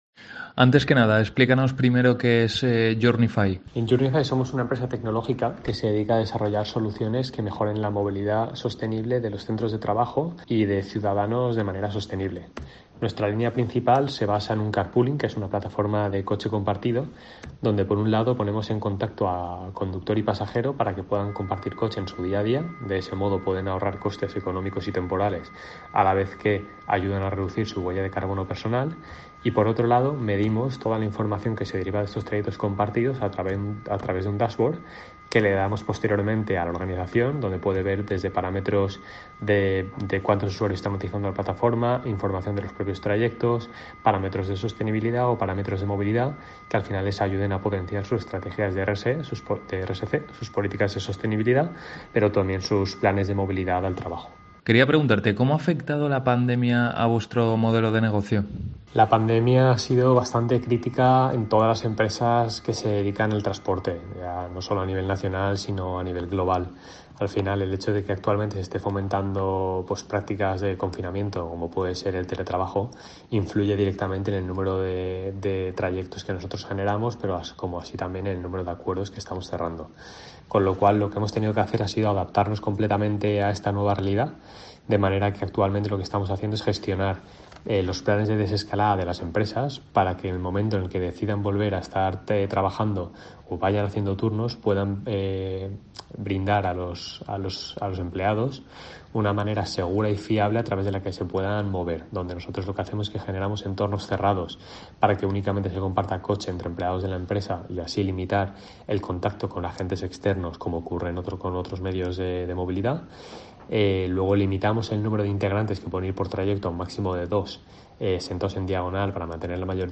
AUDIO: Entrevista